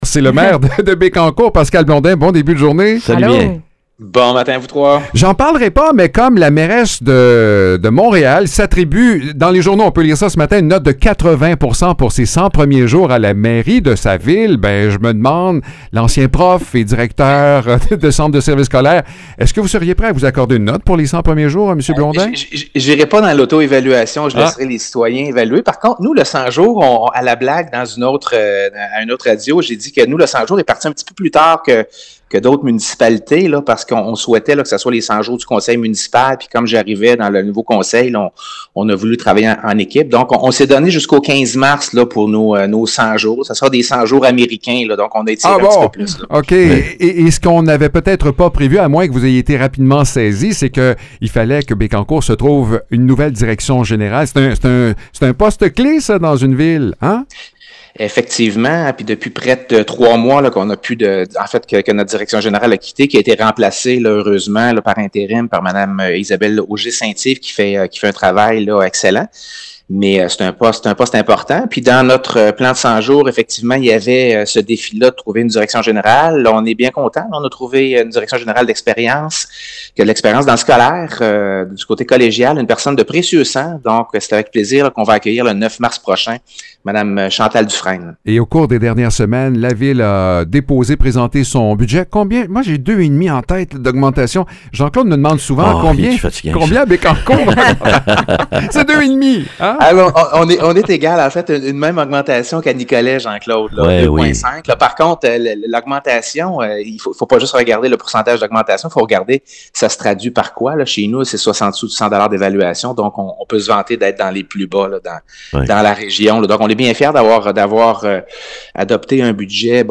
Pascal Blondin, le maire de Bécancour, nous annonce qui sera la nouvelle direction générale de Bécancour.